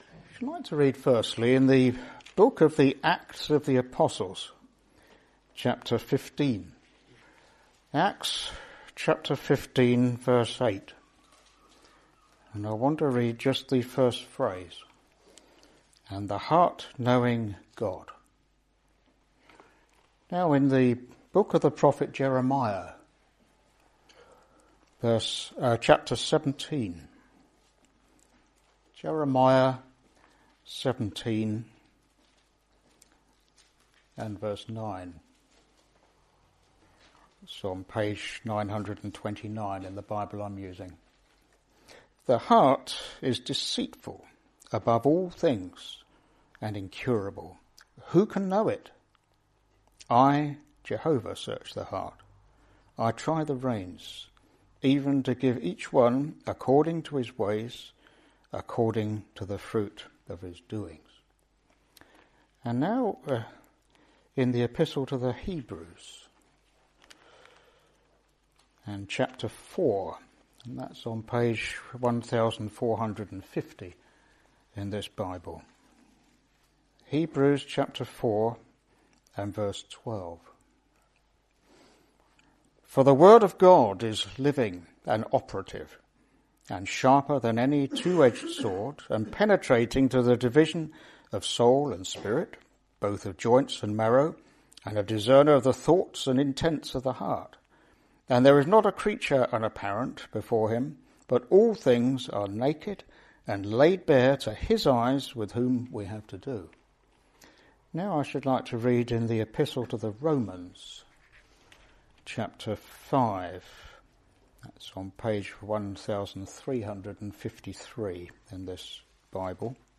God knows your heart completely—every thought, every struggle, every motive—and still loves you. This preaching reveals our true condition and points us to the incredible grace and salvation found in Jesus Christ.